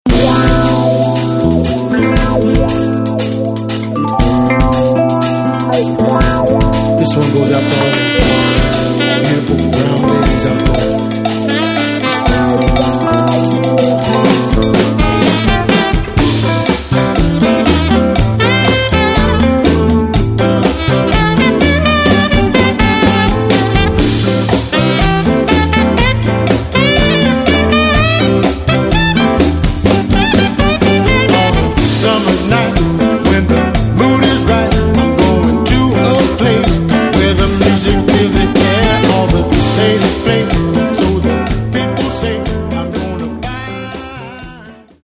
究極のチカーノ・ソウル・コンピレーション！